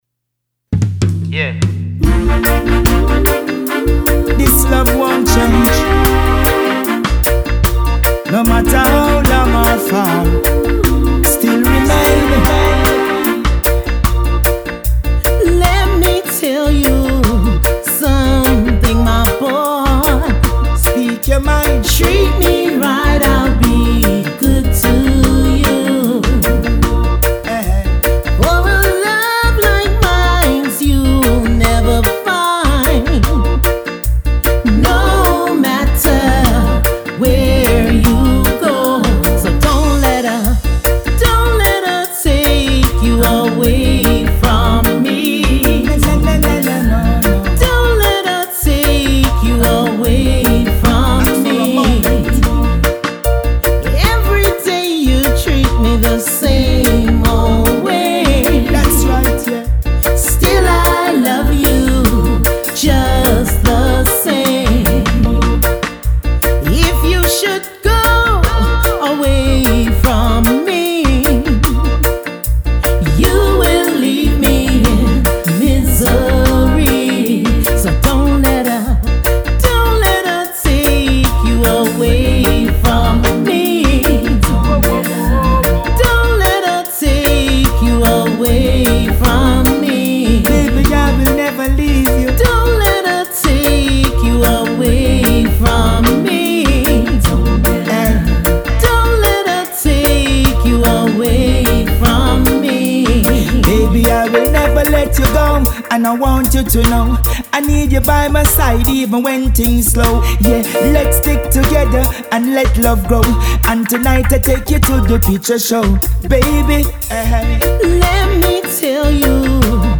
Genres: Reggae / Lovers Rock